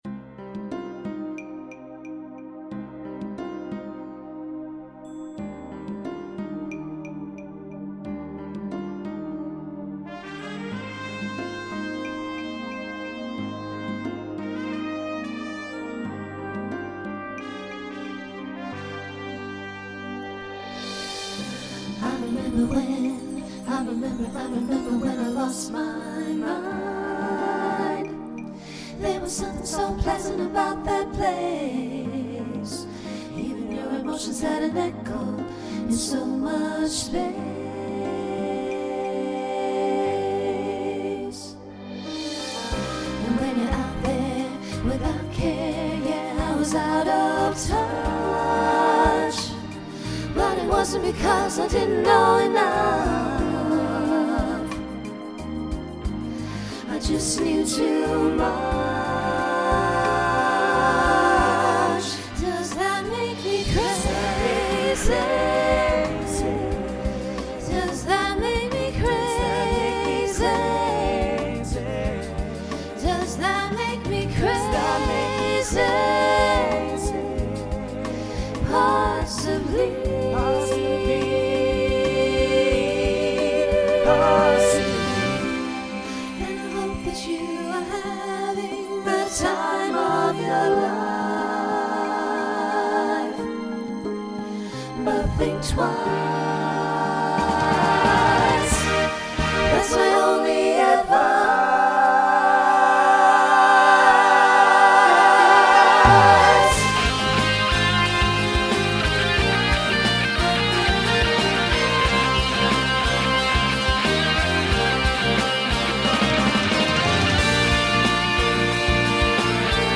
Voicing SATB Instrumental combo Genre Pop/Dance
Mid-tempo